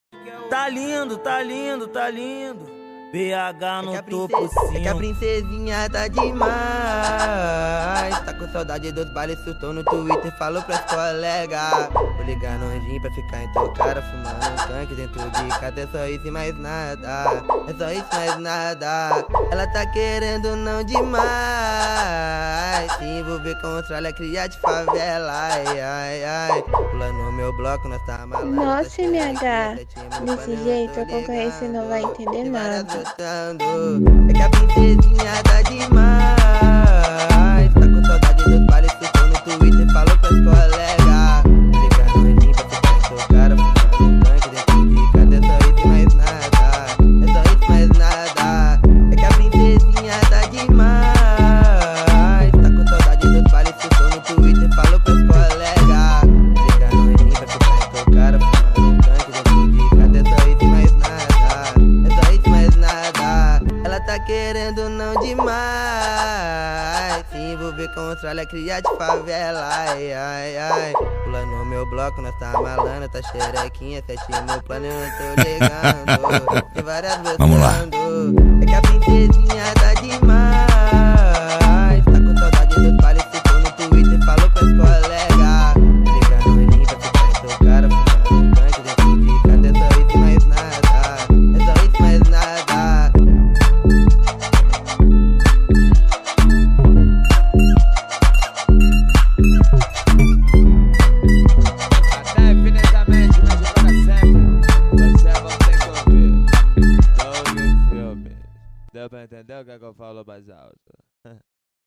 ELETROFUNK
2024-02-20 02:57:16 Gênero: Funk Views